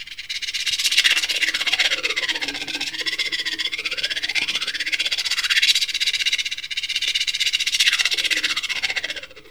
percussion 04.wav